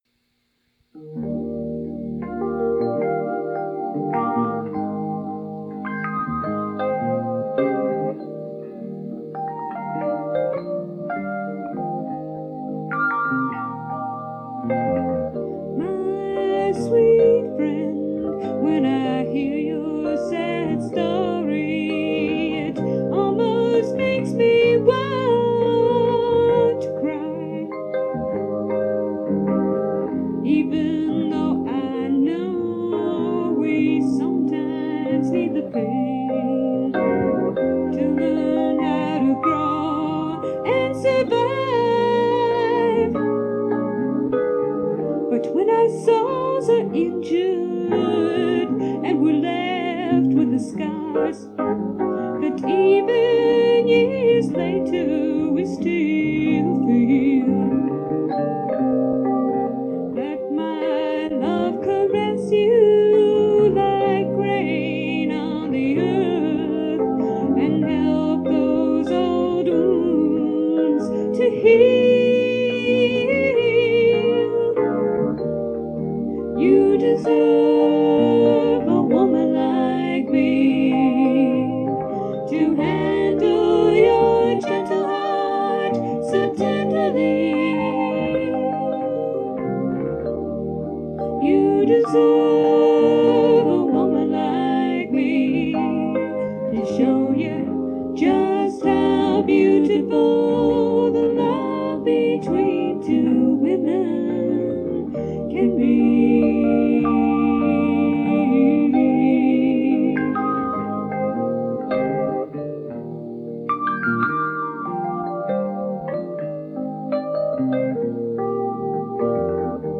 singing and playing bass
keyboard
and often singing harmony or sharing the lead vocal.
Unfortunately, some of the recordings are not the greatest in terms of quality